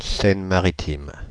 Ääntäminen
Ääntäminen France (Paris): IPA: [sɛn.ma.ʁi.tim] Haettu sana löytyi näillä lähdekielillä: ranska Käännöksiä ei löytynyt valitulle kohdekielelle.